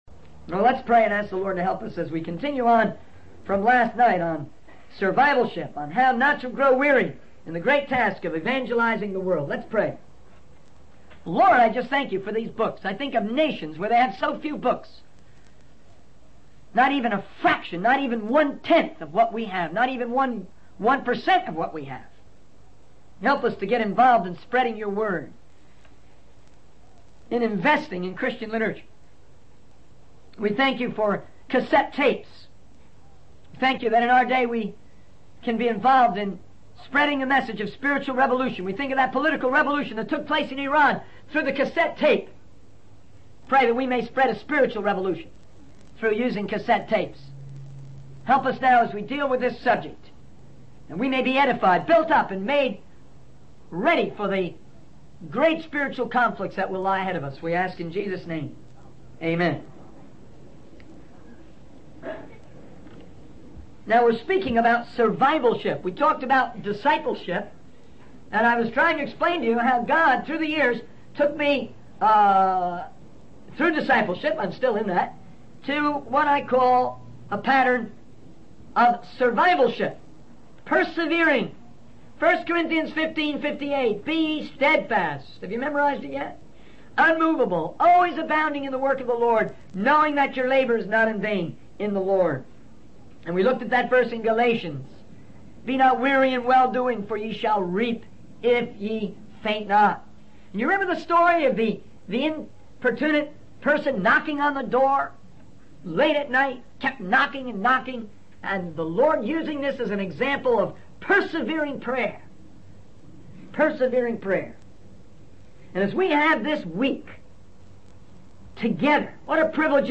In this sermon, the speaker begins by praying for the spread of God's word and the importance of investing in Christian literature. He mentions the impact of cassette tapes in spreading the message of spiritual revolution, citing the example of a political revolution in Iran. The speaker emphasizes the need to be prepared for spiritual conflicts and encourages forgiveness and letting go of bitterness.